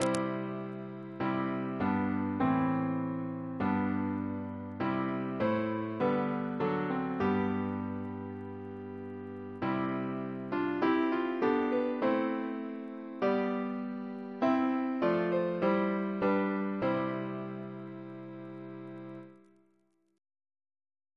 Double chant in C Composer: William Crotch (1775-1847), First Principal of the Royal Academy of Music Reference psalters: ACB: 74; ACP: 52; PP/SNCB: 67